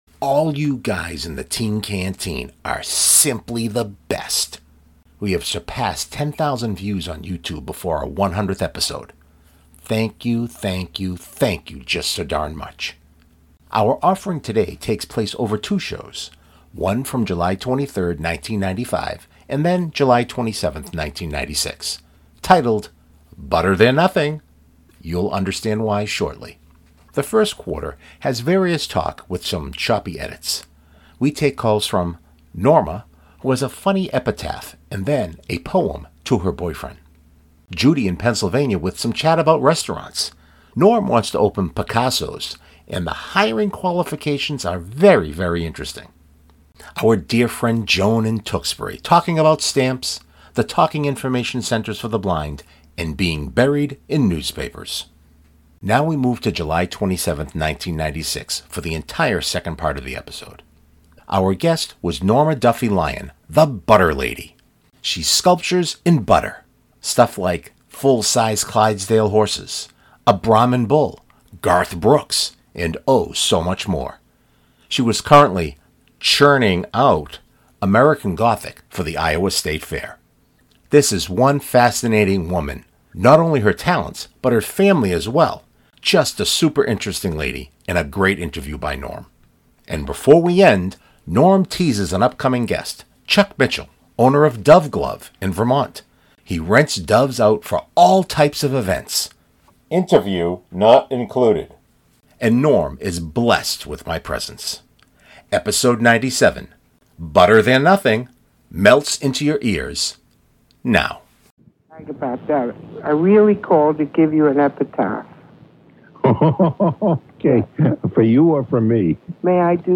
The first quarter has various talk with some choppy edits.